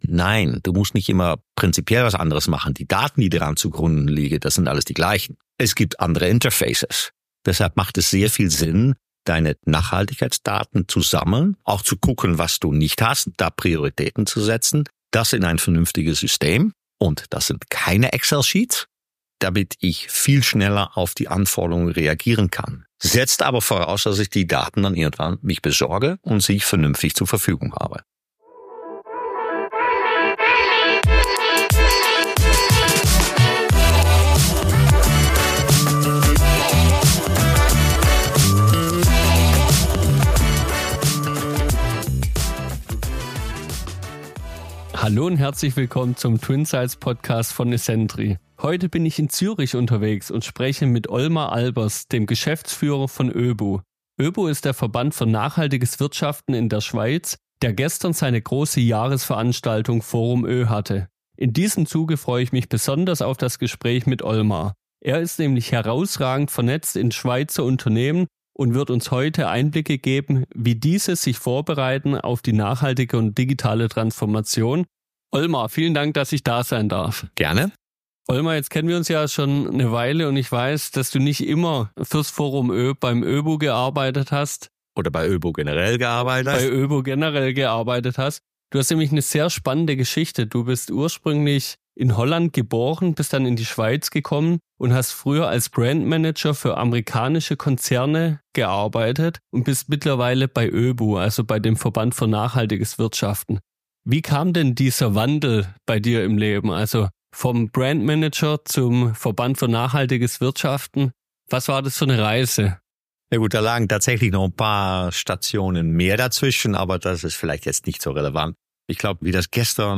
Am Rande des Jahresevents Forum Ö unterhalten sich die beiden über das Gelernte, die Stimmung der Wirtschaft in der Schweiz und was den gebürtigen Holländer vor vielen Jahren in die Schweiz gebracht hat.